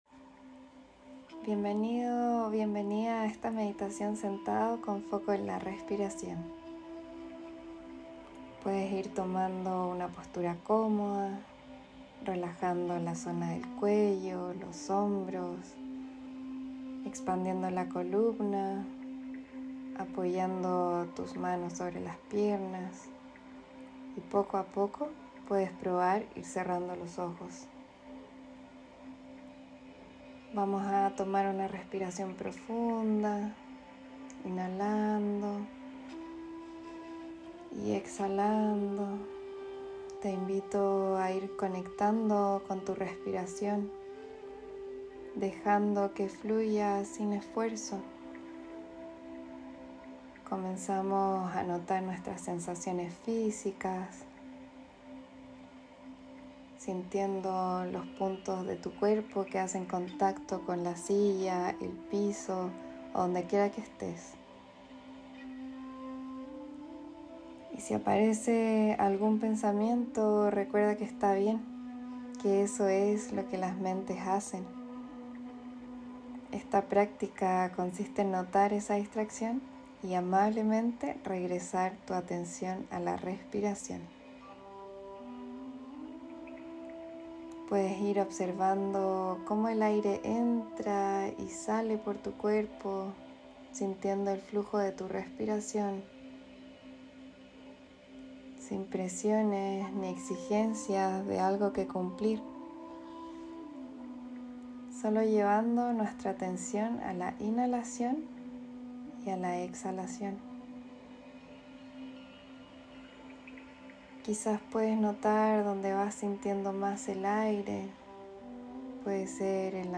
Meditación guiada con foco en la respiración.